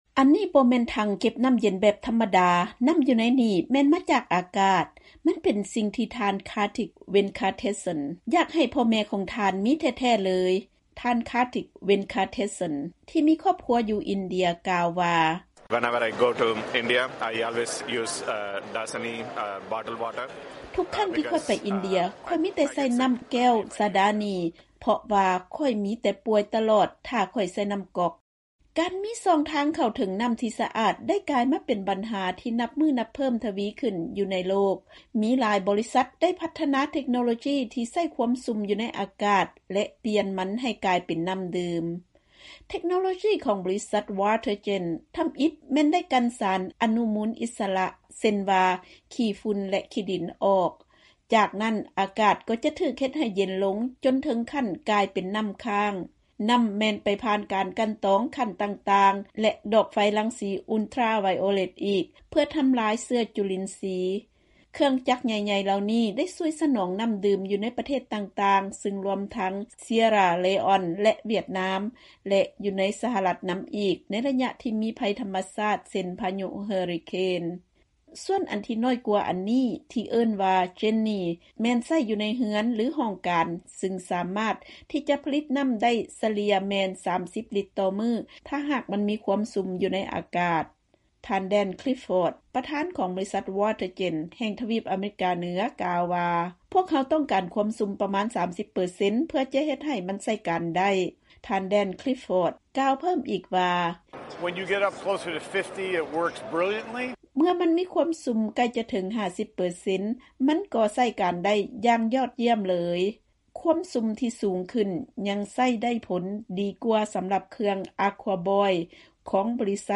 ເຊີນຟັງລາຍງານເລື້ອງການກັ່ນນໍ້າຈາກອາກາດ